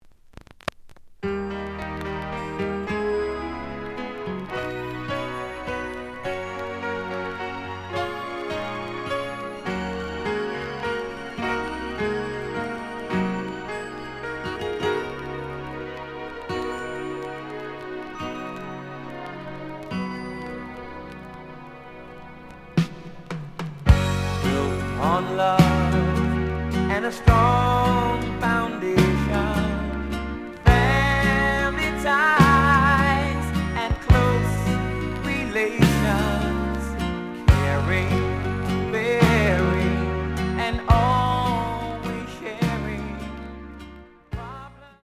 The audio sample is recorded from the actual item.
●Genre: Disco
Some click noise on beginning of B side, but almost good.)